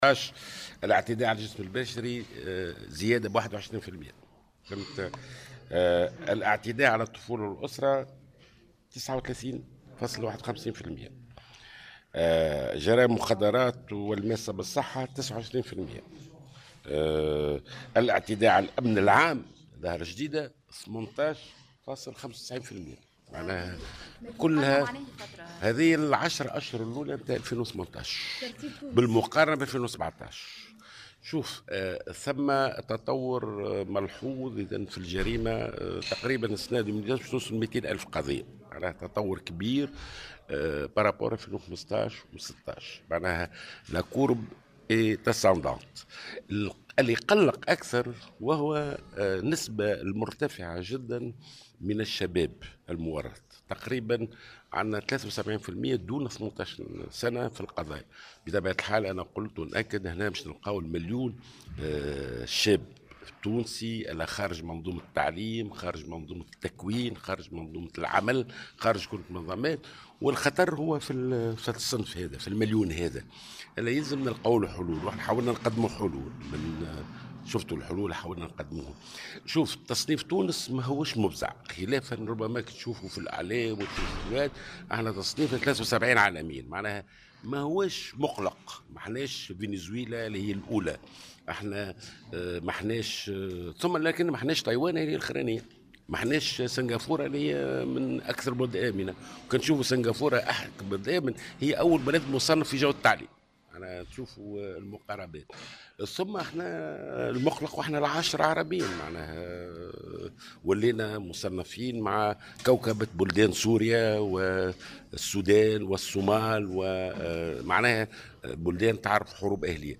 وأشار مدير المعهد التونسي للدراسات الإستراتيجية، ناجي جلول في تصريح لمراسل "الجوهرة أف أم" على هامش ندوة حول"الجريمة في تونس " إلى ارتفاع الاعتداء على الجسم البشري بنسبة 21% وارتفاع الاعتداء على الأطفال ب39%، وبـ29 بالمائة بالنسبة لجرائم المخدرات والجرائم الماسة بالصحة، وبـ18 بالمائة بالنسبة للاعتداء على الامن العام.